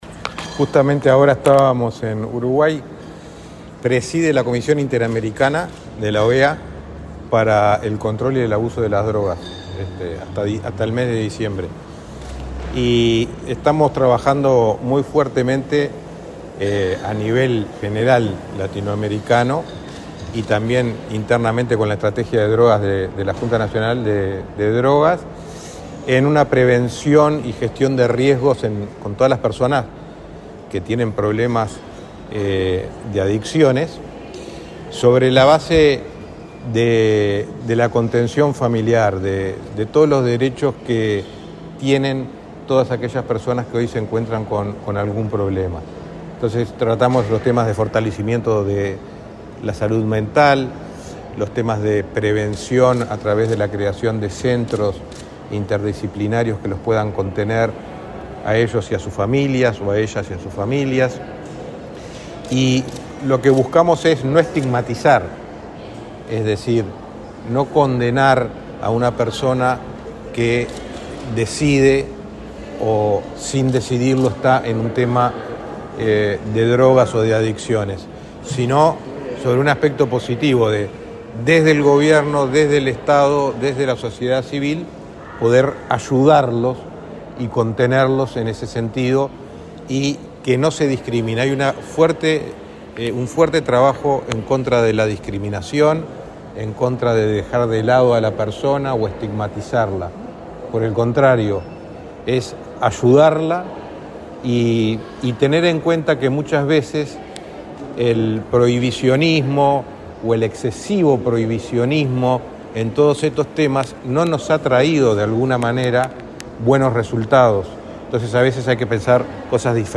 Declaraciones del secretario de Presidencia, Rodrigo Ferrés
Declaraciones del secretario de Presidencia, Rodrigo Ferrés 23/09/2024 Compartir Facebook X Copiar enlace WhatsApp LinkedIn El secretario de la Presidencia, Rodrigo Ferrés, dialogó con la prensa, luego de participar en el evento Diálogo Hemisférico sobre Derechos Humanos y Políticas de Drogas, este lunes 23 en la Torre Ejecutiva.